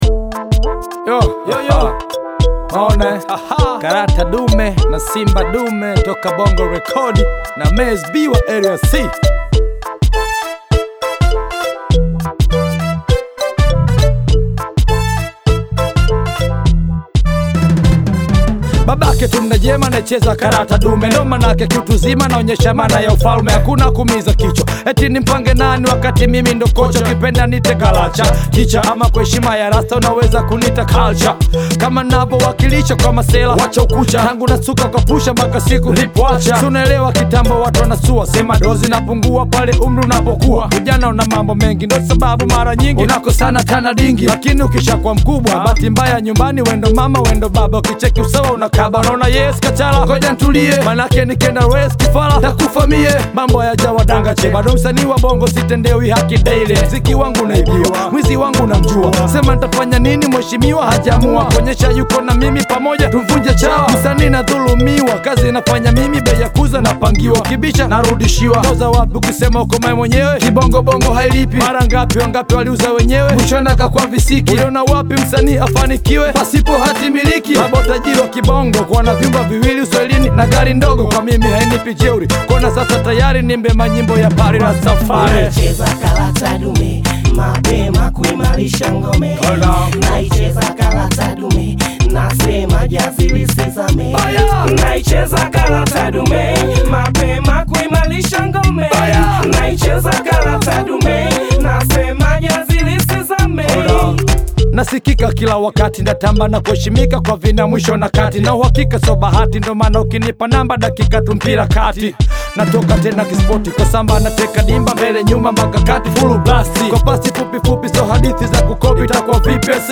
With an energetic beat and catchy hooks
is a lively composition